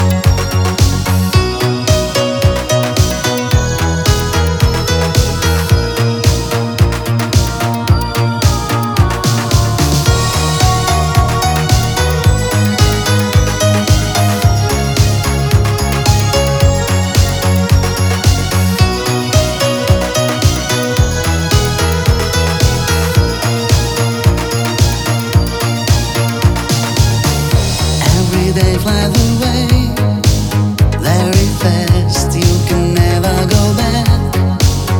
# Disco